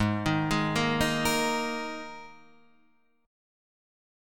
G# Suspended 2nd